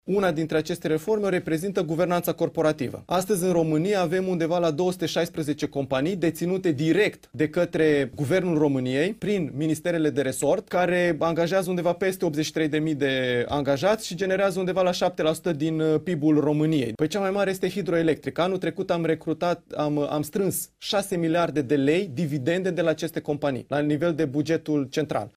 Și trebuie să facă profit, spune secretarul de stat Mihai Precup din Ministerul Finanțelor, într-o dezbatere organizată de Ziarul Financiar: